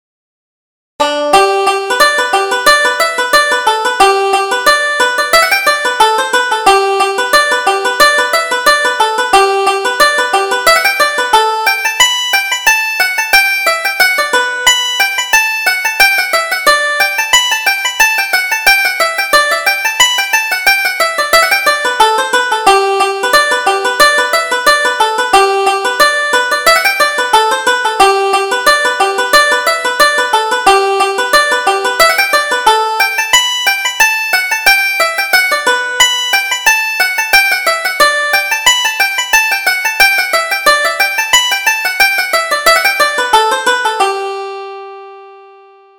Reel: Lady Mary Ramsay